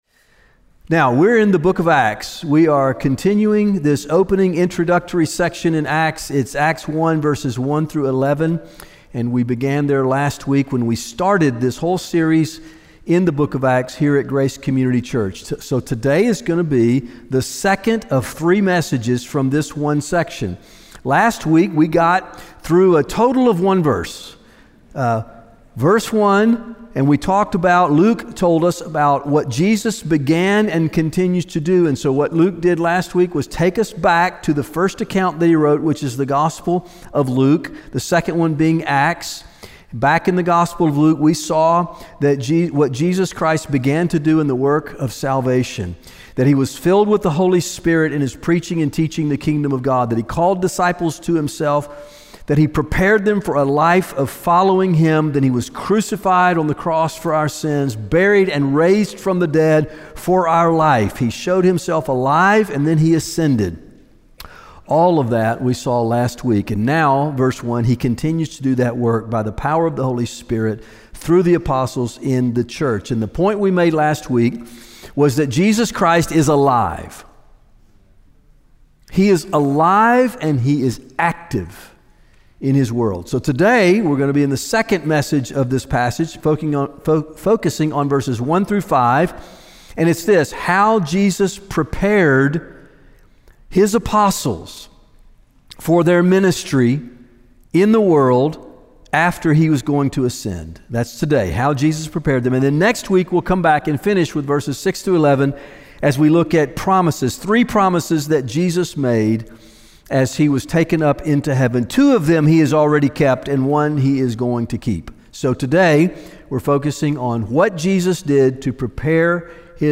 One of the ways that we pursue this mission is by gathering each Sunday for corporate worship, prayer, and biblical teaching.